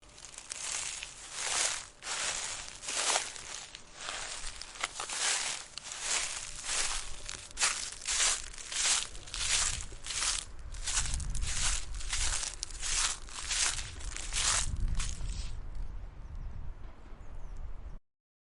Walking On Leafs
Walking On Leafs is a free nature sound effect available for download in MP3 format.
Walking on Leafs.mp3